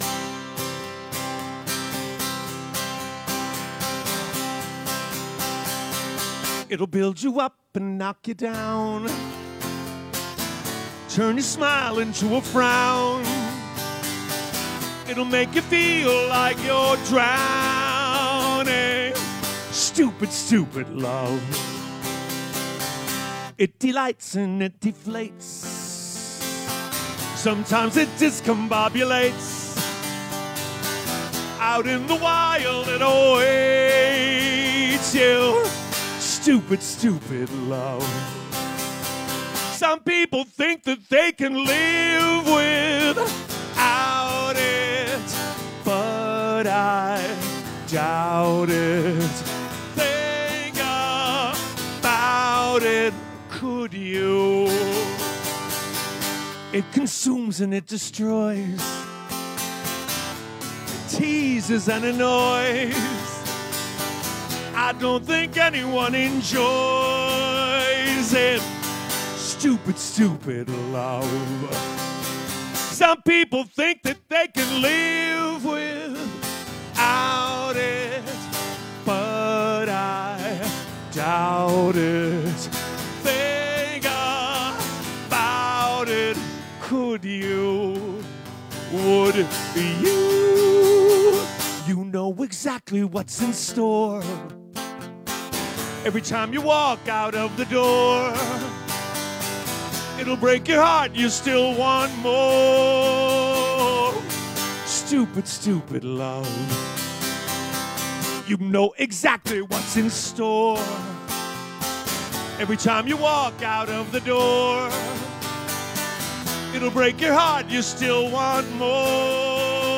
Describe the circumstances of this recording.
Recorded at Celebrity Club